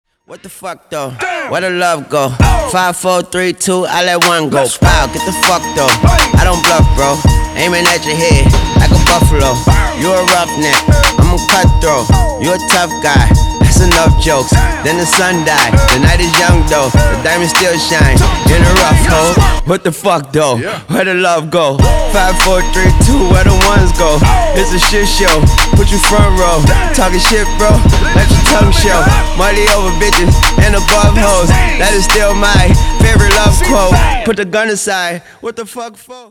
Хип-хоп
Rap